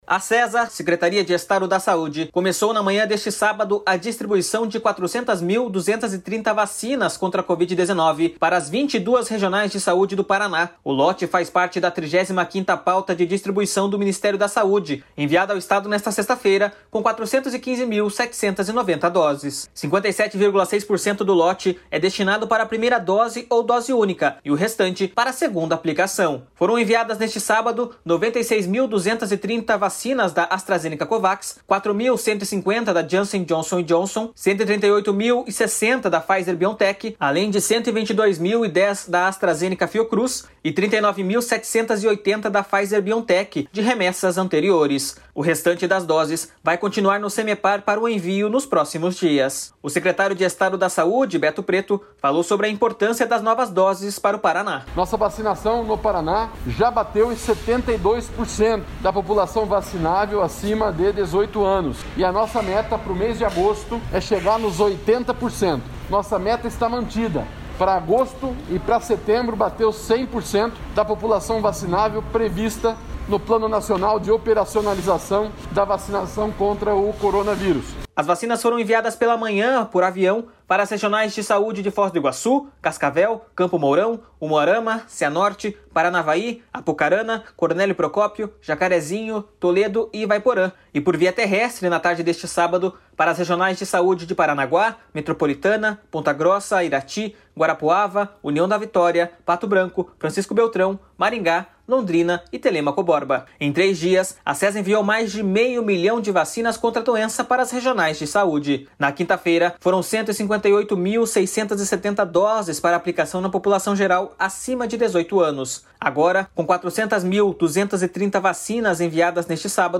O secretário de Estado da Saúde, Beto Preto, falou sobre a importância das novas doses para o Paraná.// SONORA BETO PRETO //.